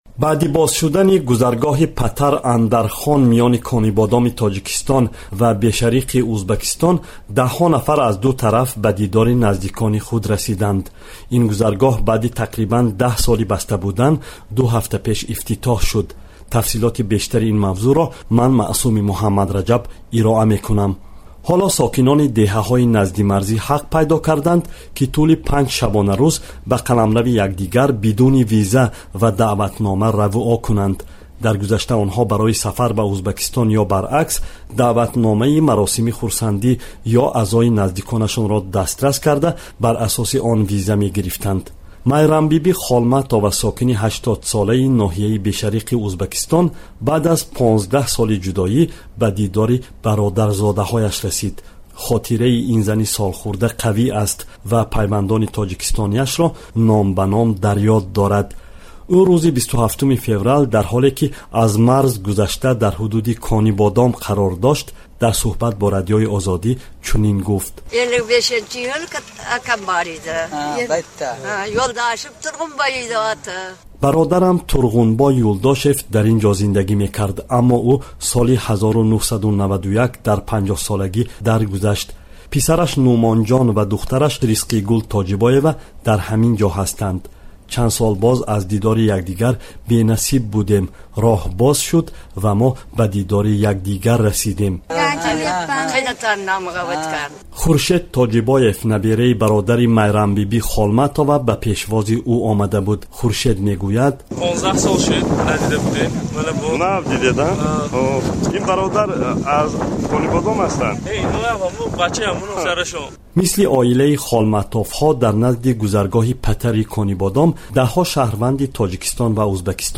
Гузориш аз марзи Тоҷикистону Узбакистон